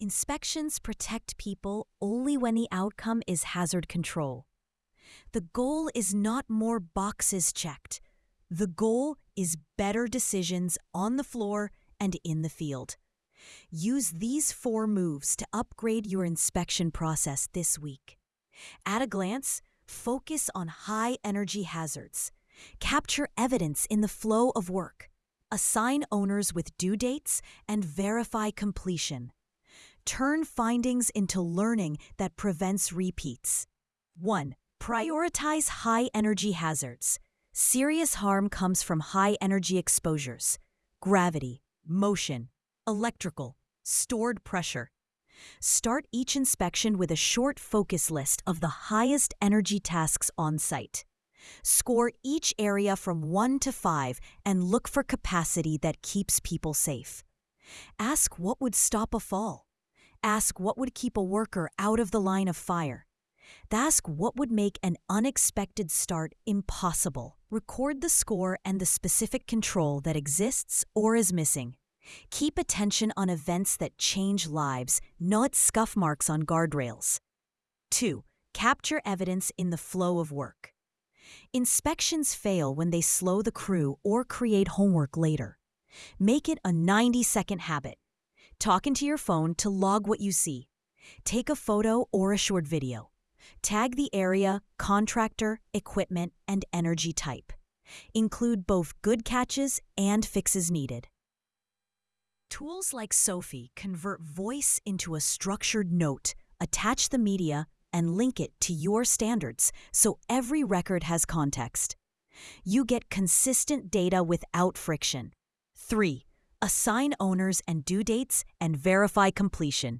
sage_gpt-4o-mini-tts_1x_2025-09-16T03_11_44-980Z.wav